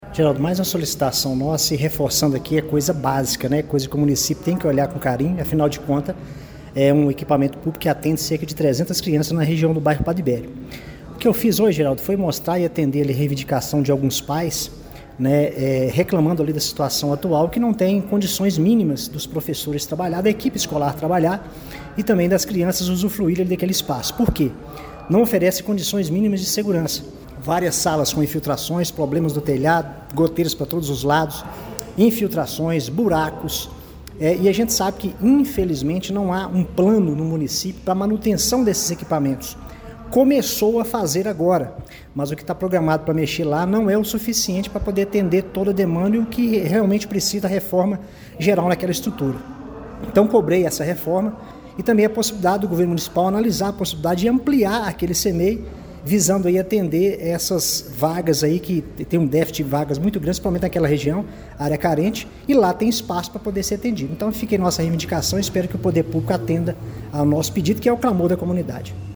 A situação do Centro Municipal de Educação Infantil Prefeito José Gentil de Almeida, conhecido como Gentilzinho, no bairro Padre Libério, foi levada à tribuna da Câmara Municipal de Pará de Minas durante a reunião ordinária desta terça-feira (19).